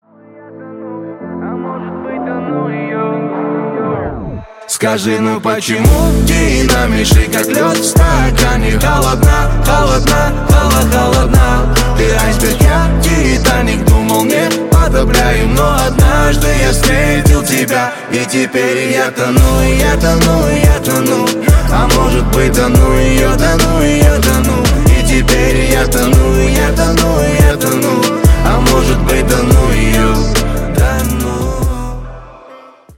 • Качество: 128, Stereo
лирика
красивый мужской голос
русский рэп
дуэт